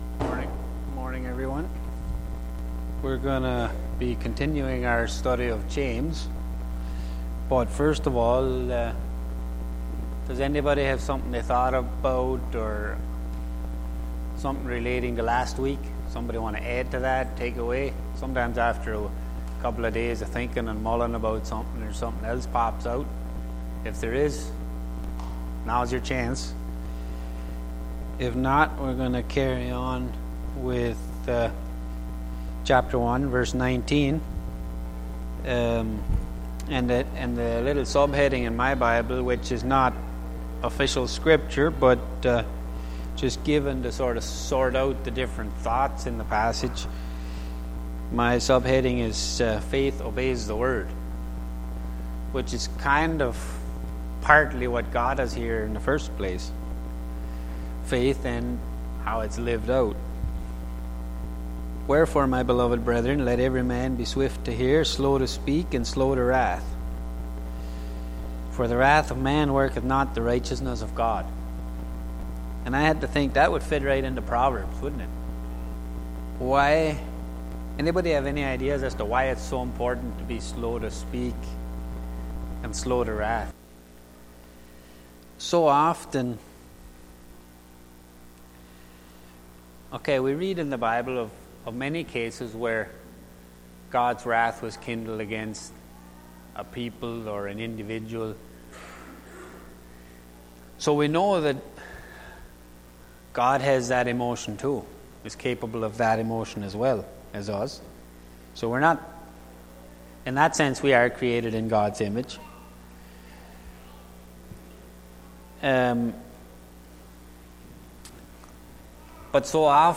Series: Sunday Morning Bible Study Service Type: Sunday Morning